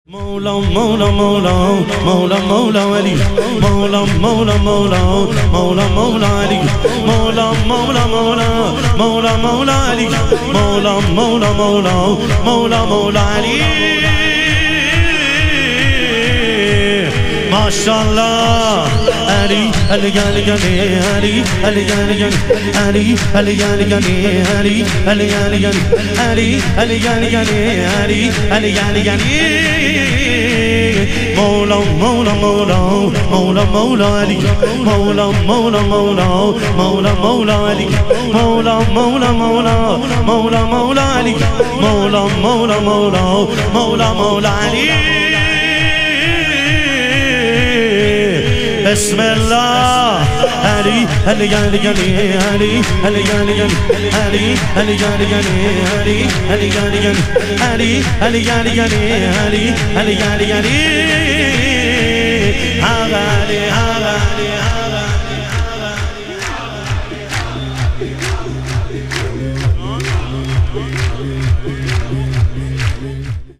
شور
شب ظهور وجود مقدس حضرت امیرالمومنین علیه السلام